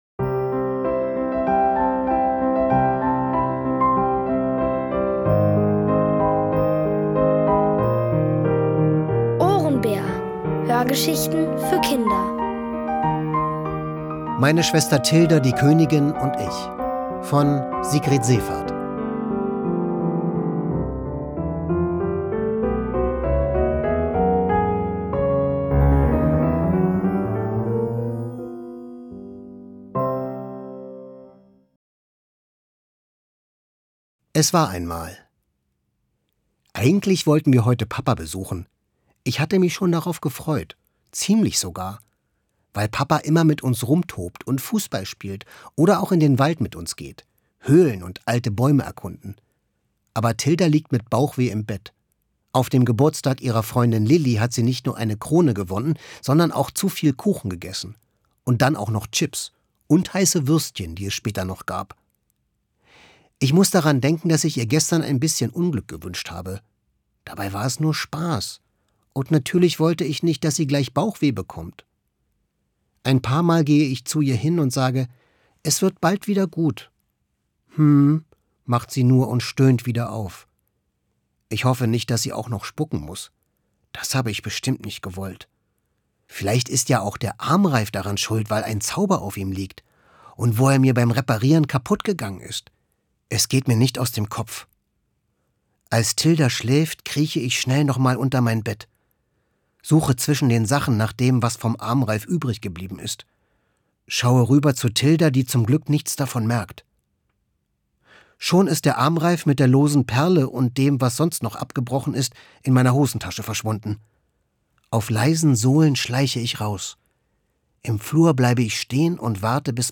Von Autoren extra für die Reihe geschrieben und von bekannten Schauspielern gelesen.
Es liest: Florian Lukas.